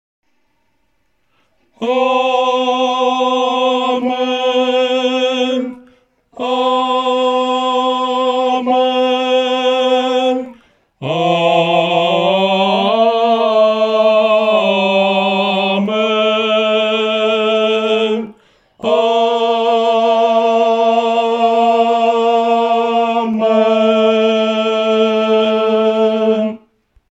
男高
本首圣诗由网上圣诗班录制